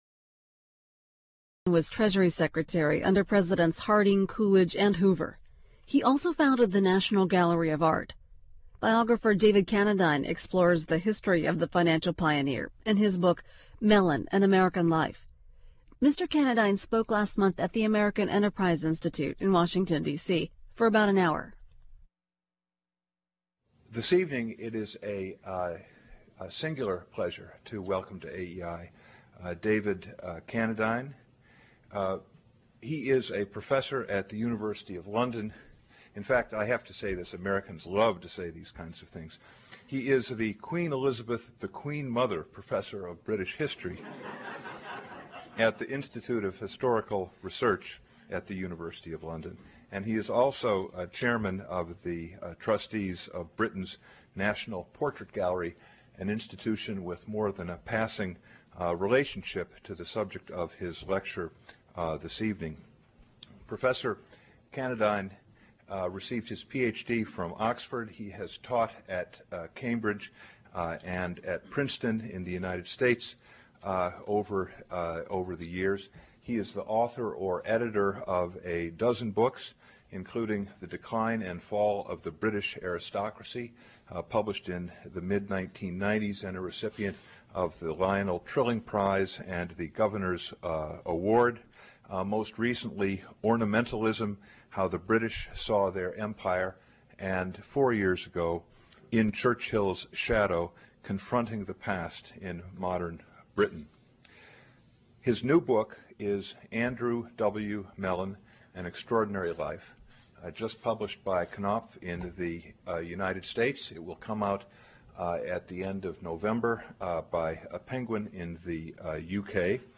Book TV talk